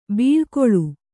♪ bīḷkoḷu